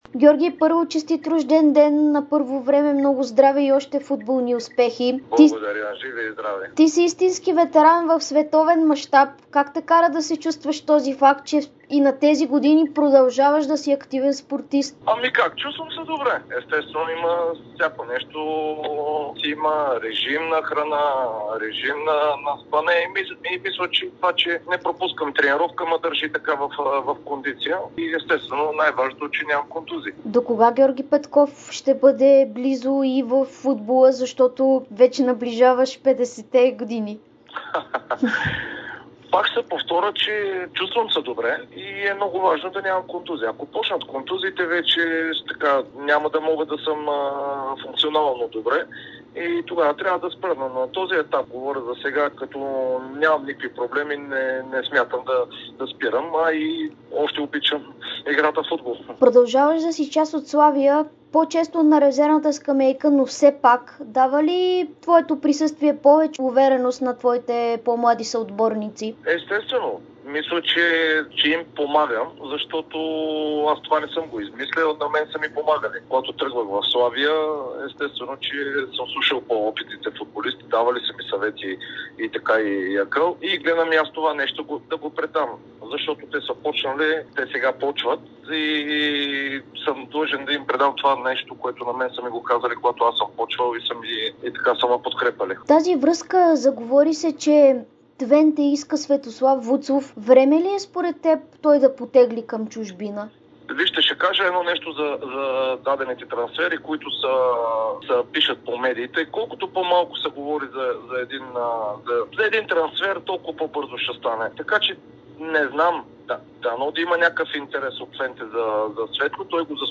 Вратарят на Славия Георги Петков, който днес навършва 47 години, даде интервю пред Дарик и dsport, в което говори за дълголетието си в спорта, помощта към младите, трансфер на Светослав Вуцов, битката за Европа, мнението му за скандалите в Левски и прогноза кой ще стане шампион на България.